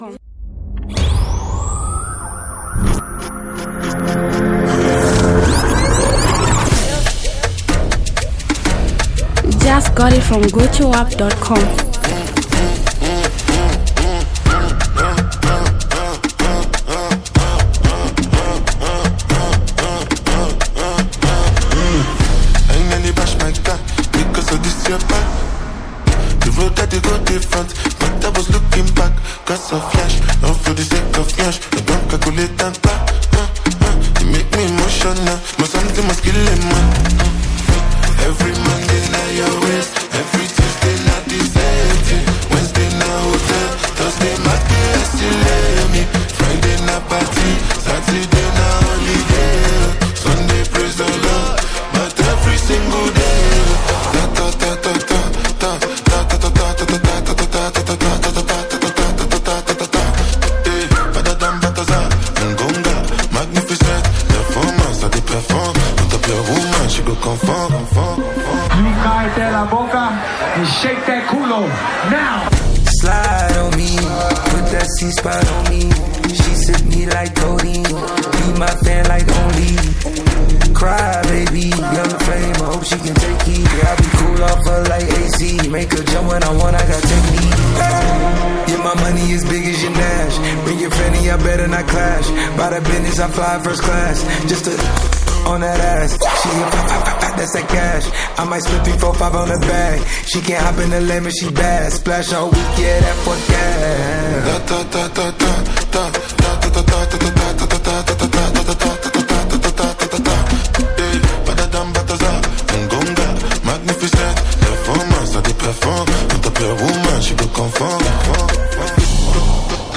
Afrobeat expertise blend seamlessly